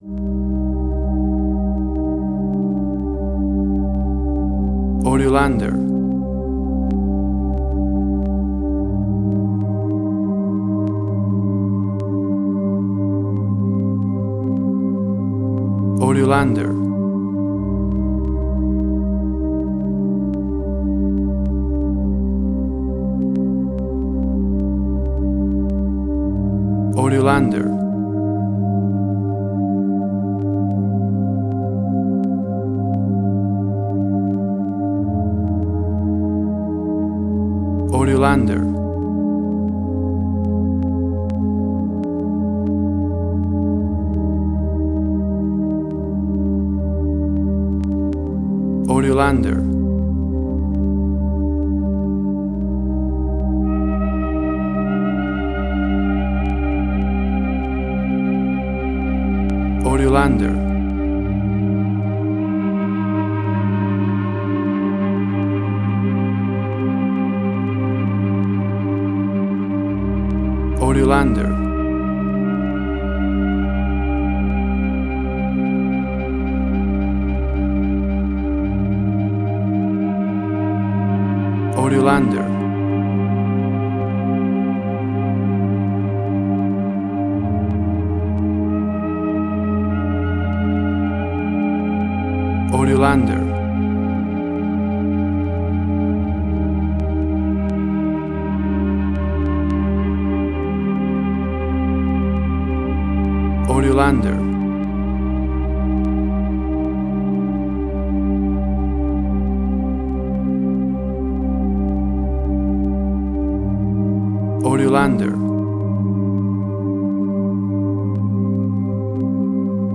Tempo (BPM): 60